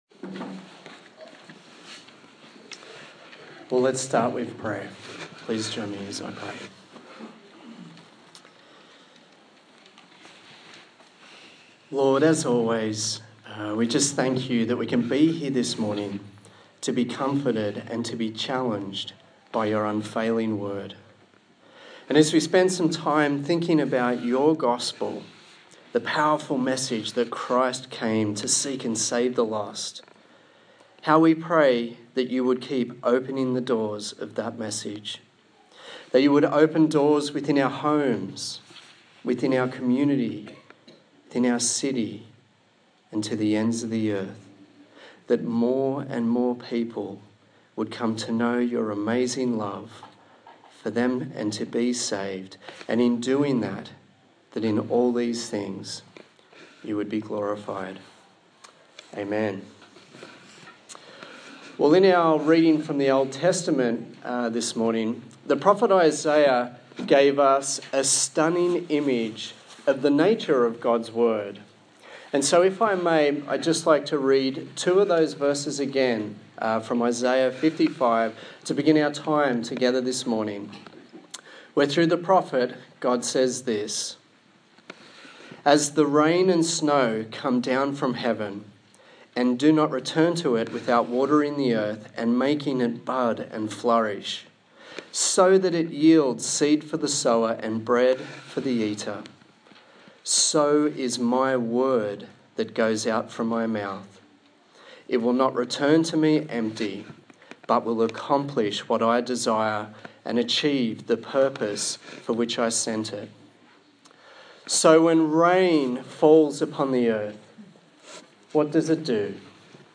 Colossians Passage: Colossians 4:2-6 Service Type: Sunday Morning